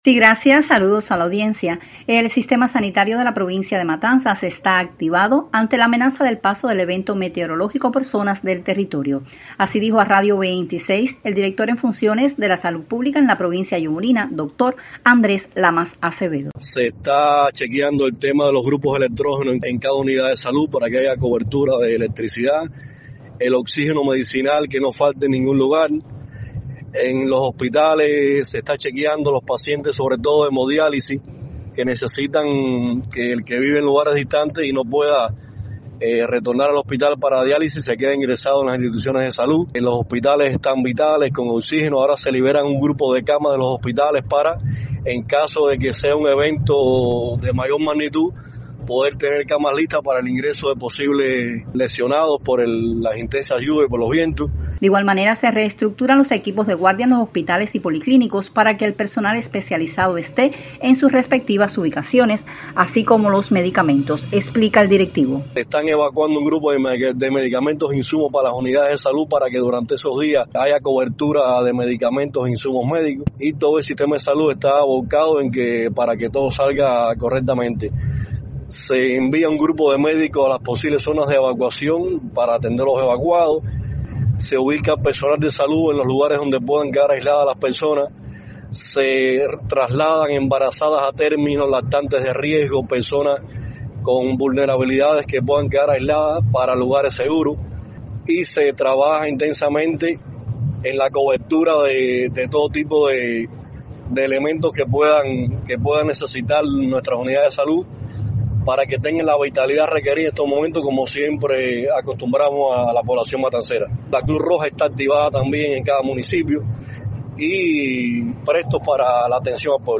El sistema sanitario de la provincia de Matanzas está activado ante la llegada del evento meteorológico que amenaza a Cuba, así dijo a la emisora Radio 26 el director en funciones de la Salud Pública en el territorio matancero, doctor Andrés Lamas Acevedo.